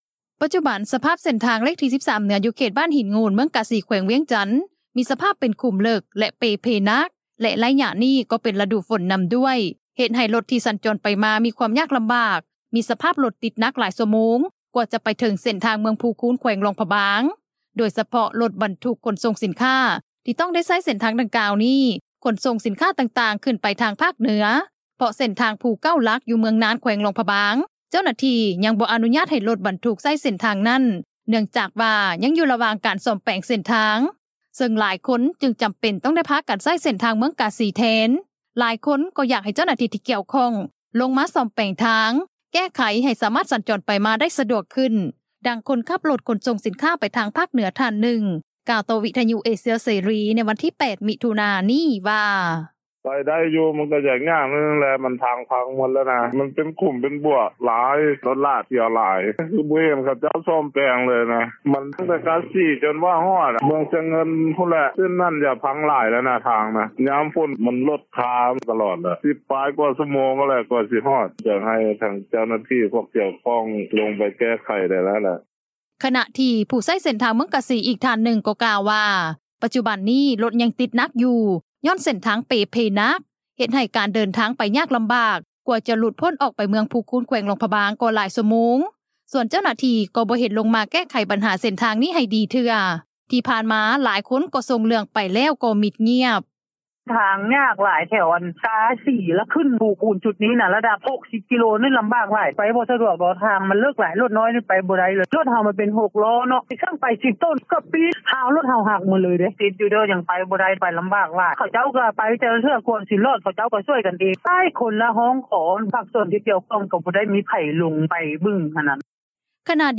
ດັ່ງຄົນຂັບຣົຖຂົນສົ່ງສິນຄ້າ ໄປທາງພາກເໜືອ ທ່ານນຶ່ງ ກ່າວຕໍ່ວິທຍຸ ເອເຊັຽ ເສຣີ ໃນວັນທີ 8 ມິຖຸນາ ນີ້ວ່າ: